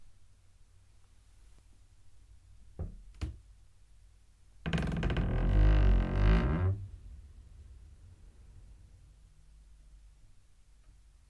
Open/Left wardrobe Door
Duration - 11s Environment - Inside very small bathroom fully tiled. Description - Left wooden door opens slowly, stiff and clatters.